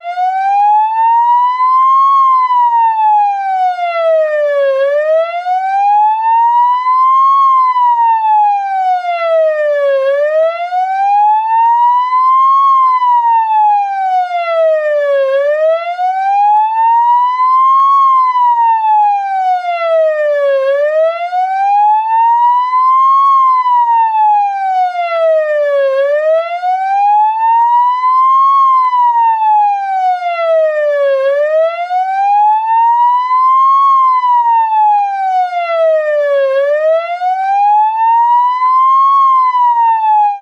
Sirena de ambulancia continua